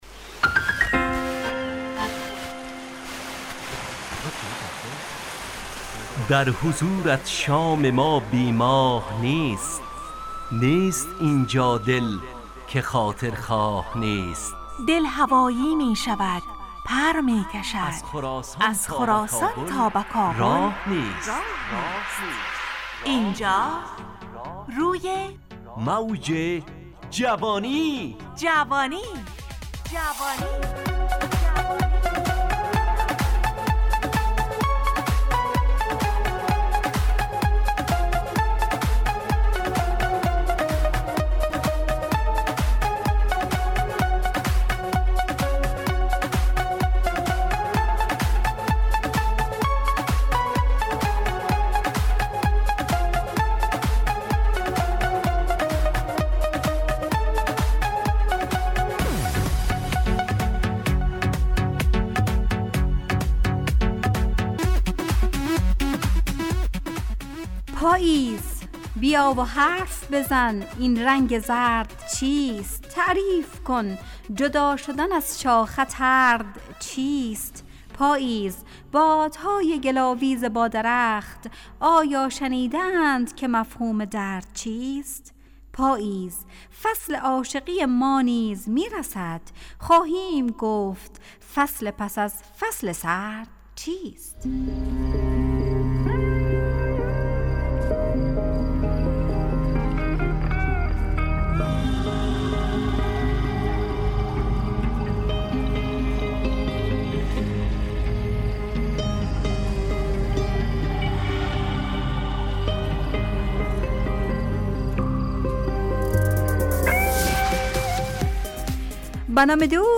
روی موج جوانی، برنامه شادو عصرانه رادیودری.
همراه با ترانه و موسیقی مدت برنامه 55 دقیقه . بحث محوری این هفته (دوستی) تهیه کننده